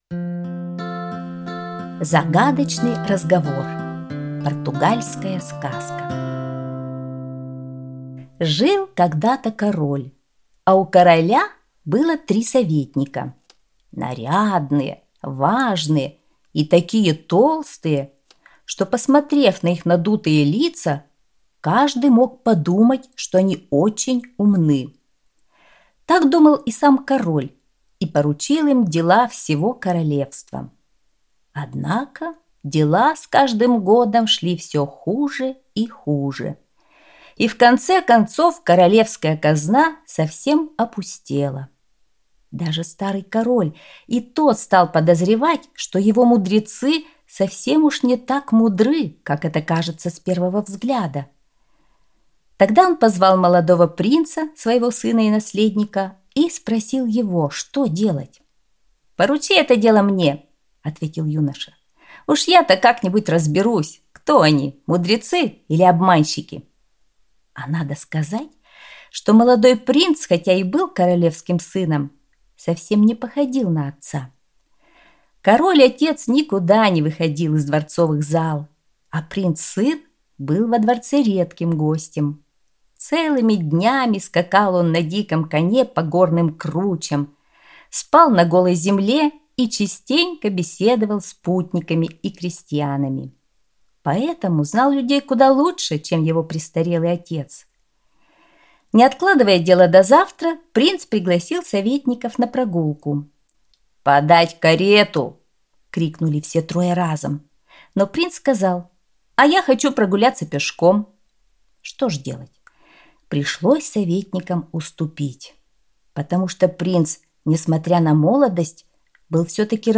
Аудиосказка «Загадочный разговор»